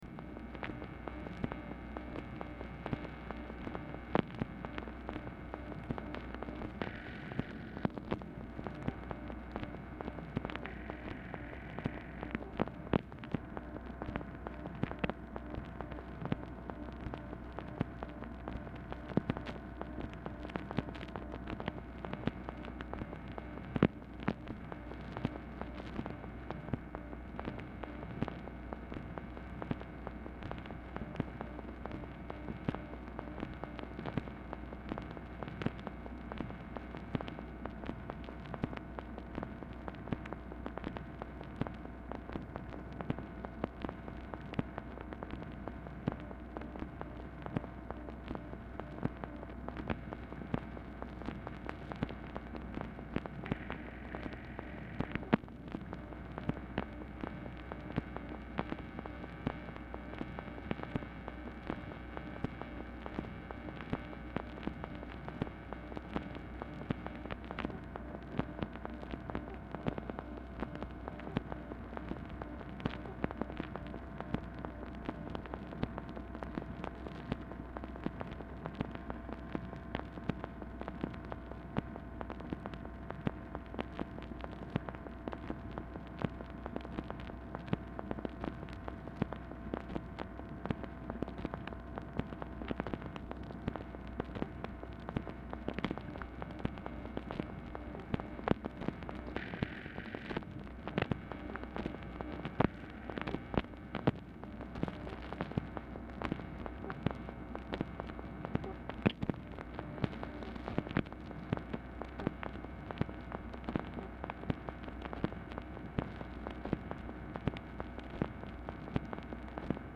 Telephone conversation # 13829, sound recording, MACHINE NOISE, 12/30/1968, time unknown | Discover LBJ
Telephone conversation
Format Dictation belt